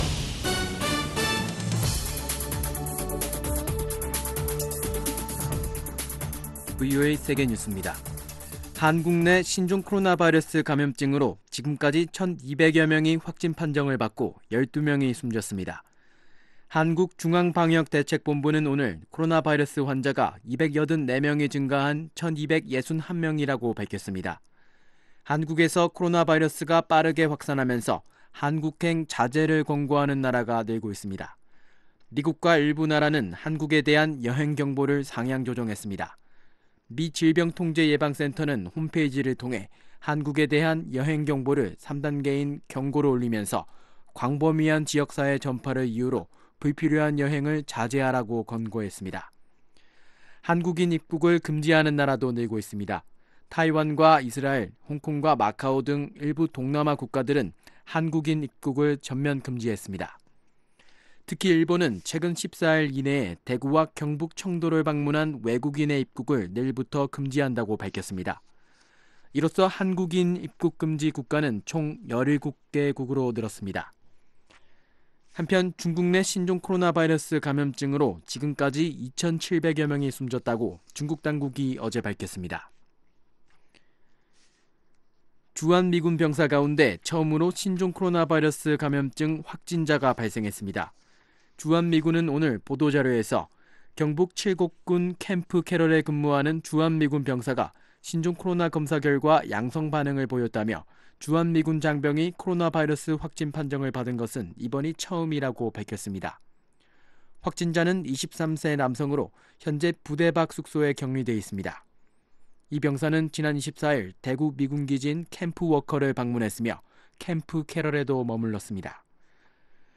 VOA 한국어 간판 뉴스 프로그램 '뉴스 투데이', 2020년 2월 26일 3부 방송입니다. 한국의 신종 코로나바이러스 확진자가 1200명을 넘어섰고, 주한미군 병사 가운데 첫 확진자가 나왔습니다. 미국의 질병통제예방센터(CDC)가 신종 코로나바이러스 감영증과 관련해 한국에 대한 여행 경보를 최고 등급으로 격상하고 불필요한 여행을 자제하라고 권고했습니다.